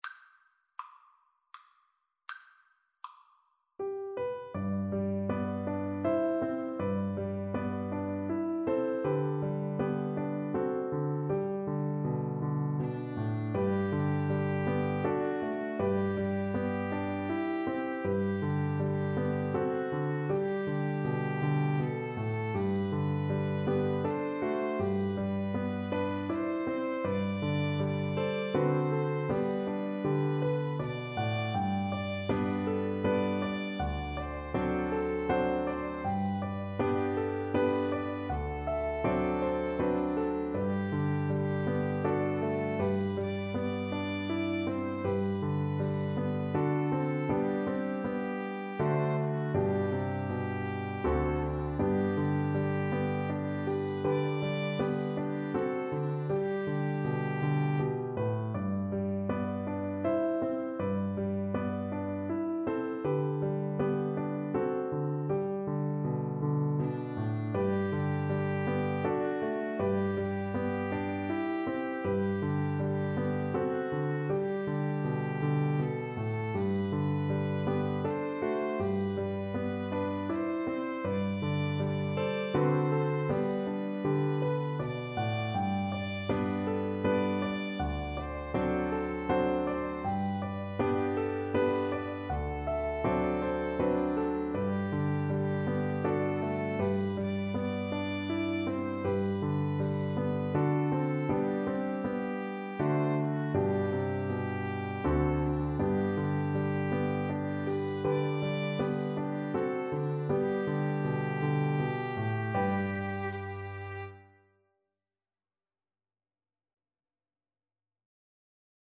Free Sheet music for Piano Trio
ViolinCelloPiano
G major (Sounding Pitch) (View more G major Music for Piano Trio )
3/4 (View more 3/4 Music)
Andante
Traditional (View more Traditional Piano Trio Music)
world (View more world Piano Trio Music)